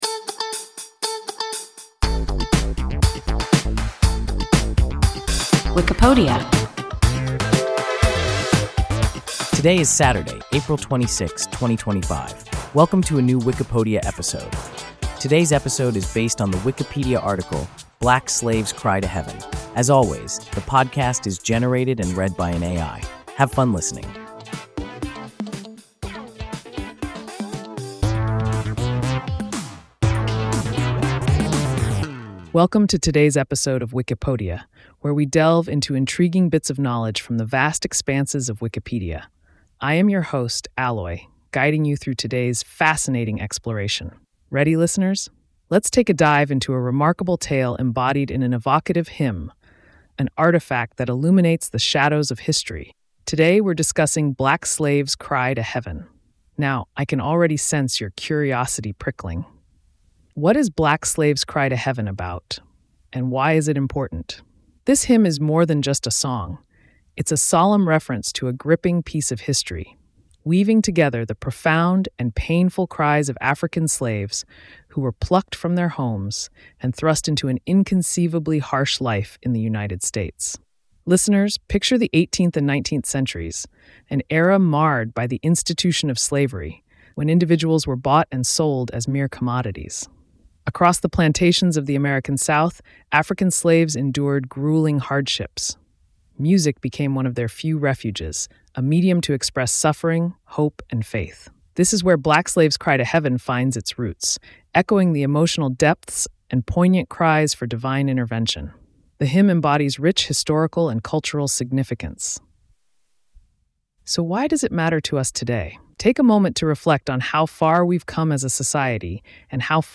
Black Slave’s Cry to Heaven – WIKIPODIA – ein KI Podcast